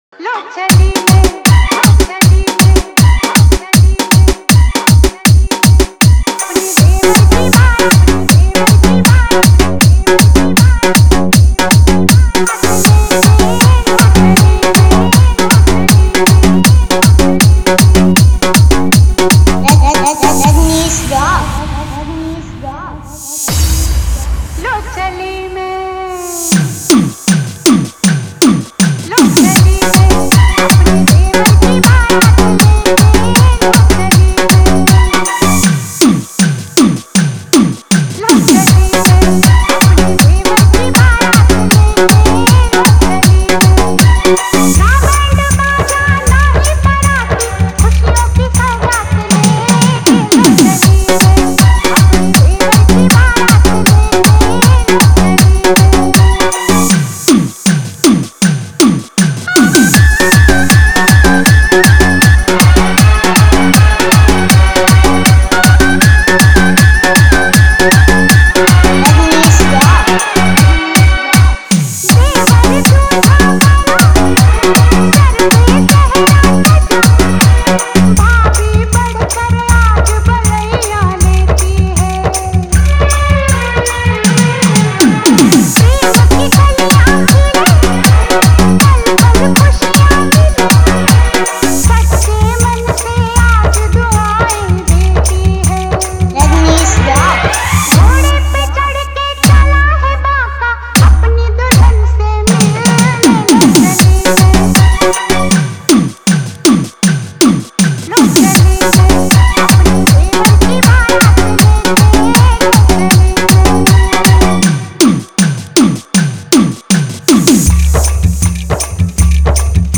Wedding Dj Song